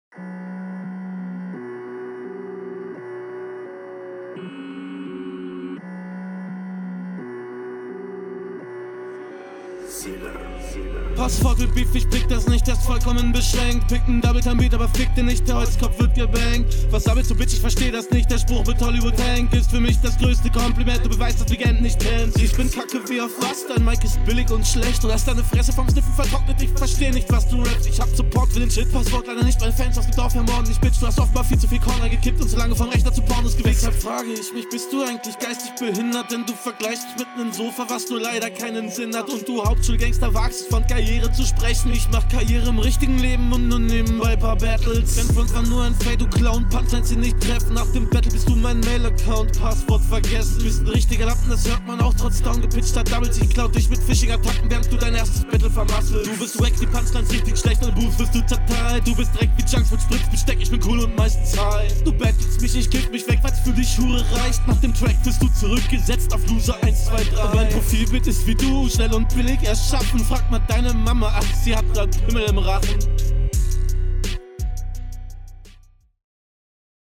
sehr gut gedachter flow nicht so sicher umgesetzt
Einstieg ist etwas off nuschelst etwas, mach den mund vielleicht etwas mehr auf beim rappen …